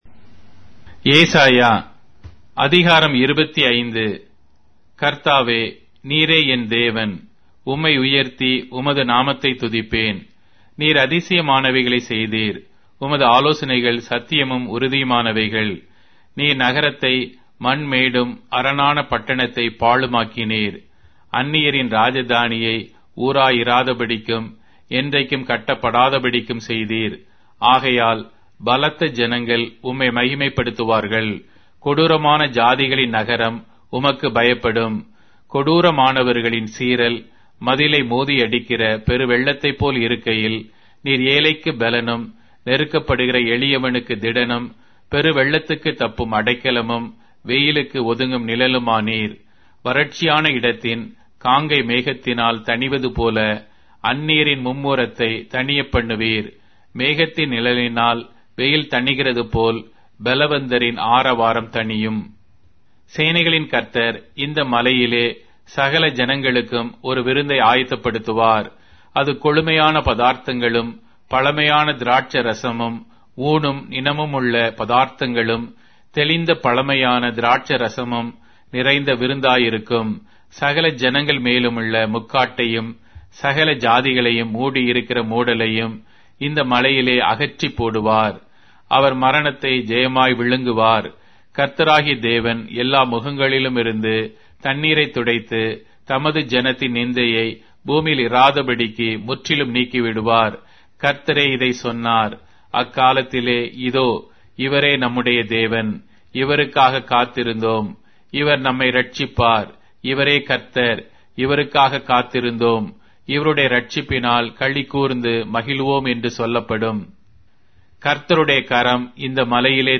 Tamil Audio Bible - Isaiah 32 in Ocvkn bible version